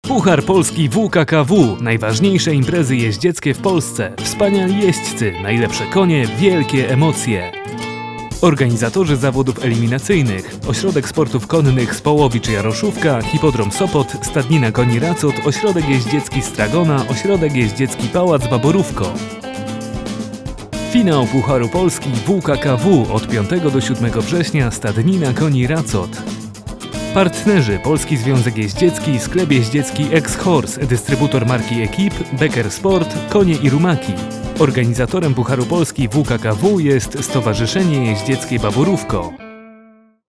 spotu audio i zobacz rankingi Pucharu Polski WKKW przed II Eliminacją w Sopocie: